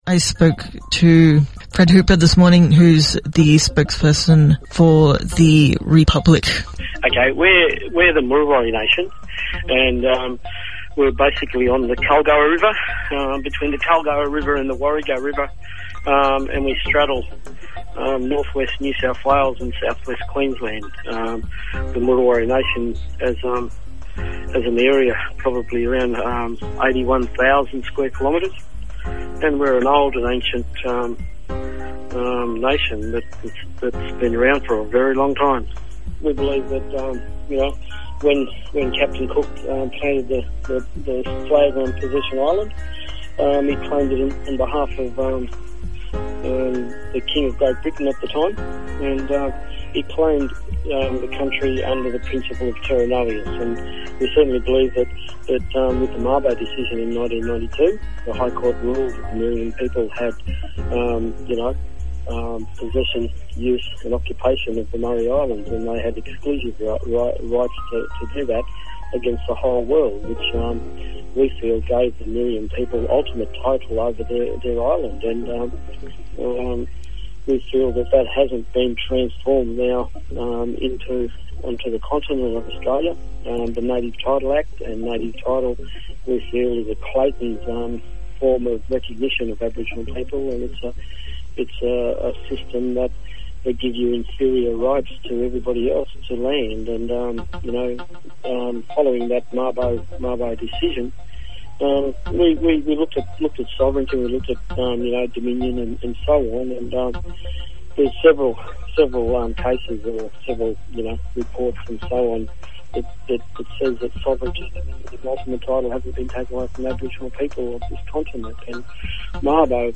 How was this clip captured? Sound quality is low but is recommended listening to those who are following this First Nations initiative.